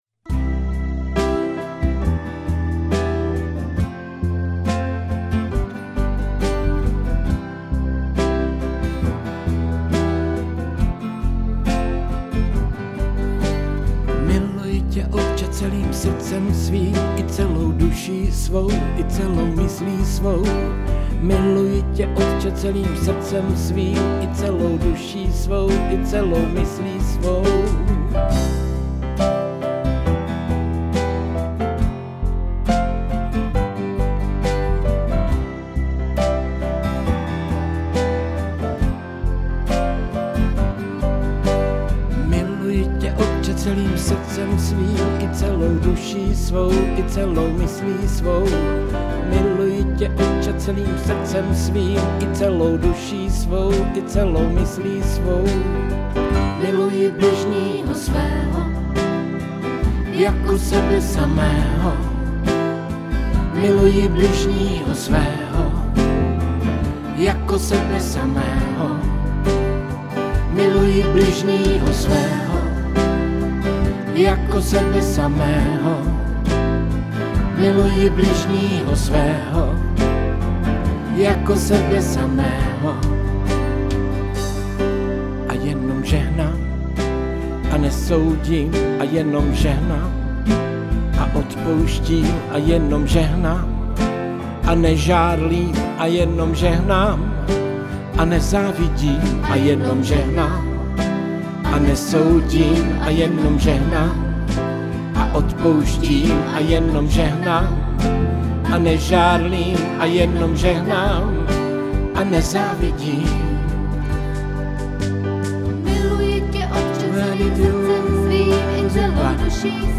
Křesťanské písně
Písně ke chvále a uctívání